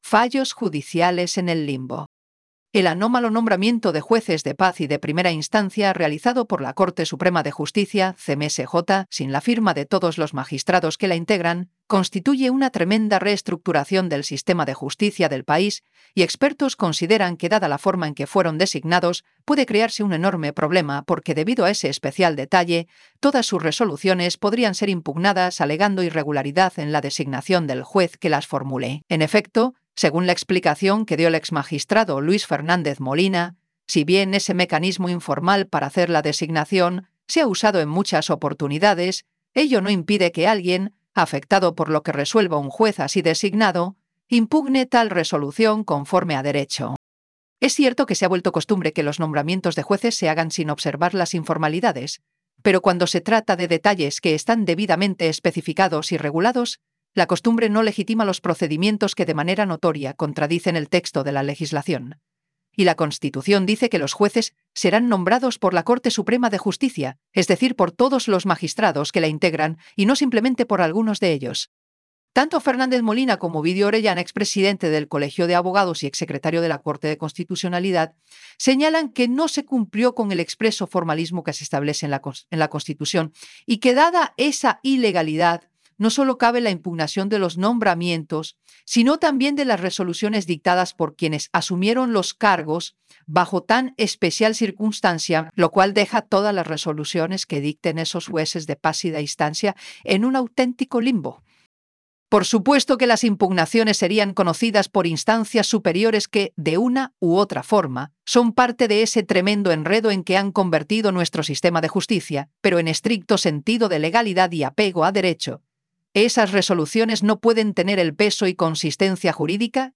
Editorial_PlayAI_Fallos_judiciales_en_el_limbo.wav